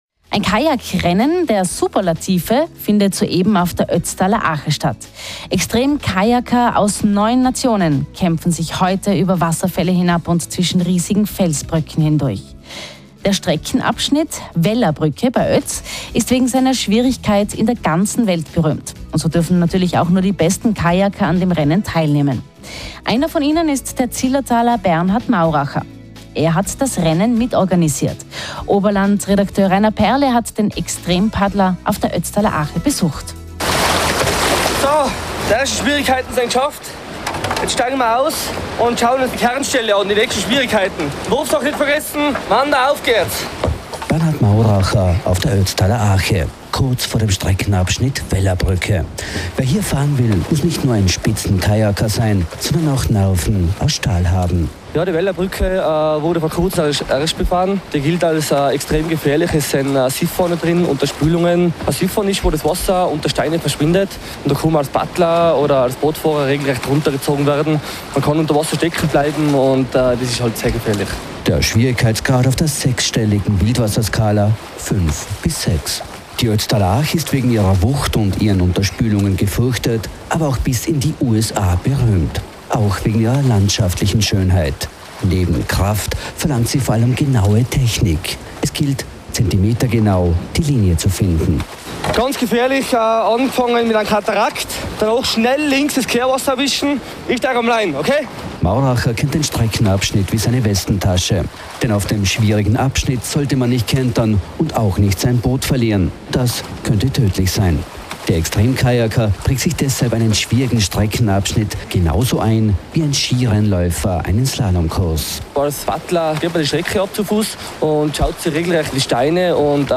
Radio Tirol Interviews